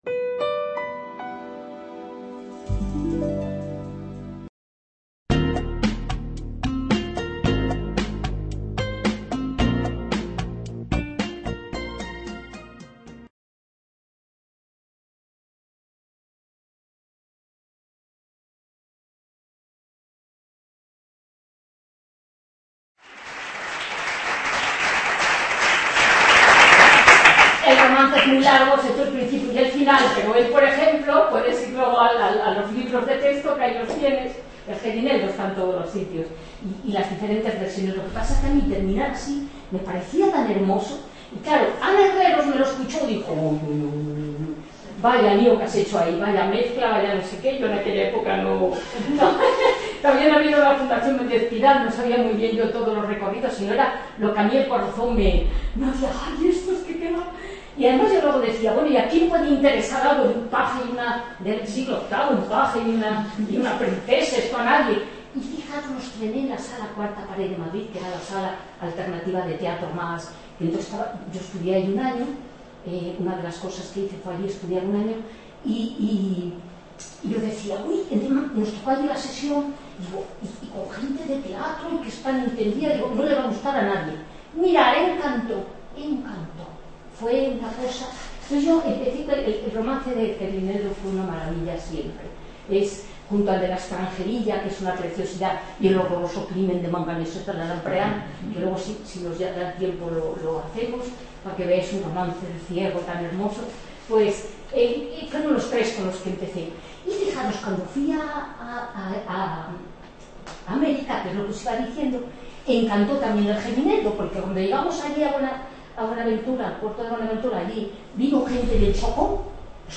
conferencia
Authorship & License License Rights BY-NC-SA Público Academic Information Room Aula Virtual del Centro Asociado de Jaén Attached Resources Attached Resources Video Movil Audio